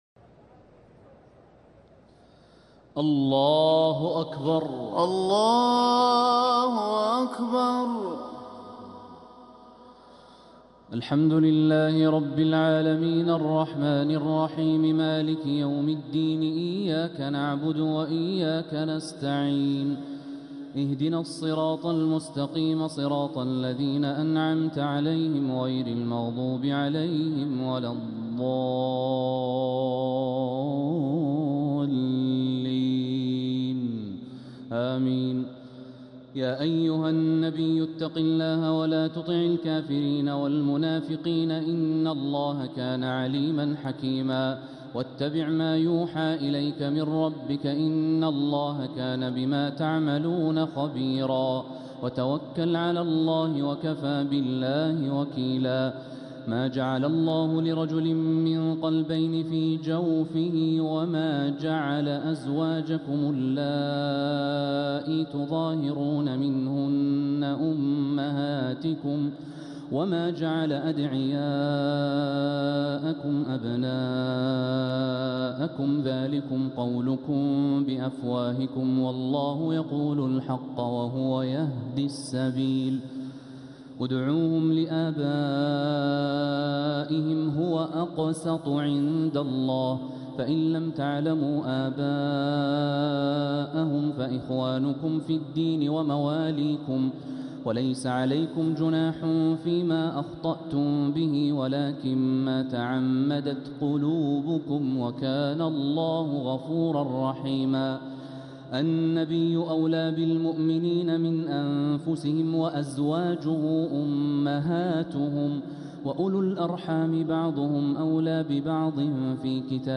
تراويح ليلة 24 رمضان 1446هـ فواتح الأحزاب (1-48) | taraweeh 24th night Ramadan 1446H surah Al-Ahzab > تراويح الحرم المكي عام 1446 🕋 > التراويح - تلاوات الحرمين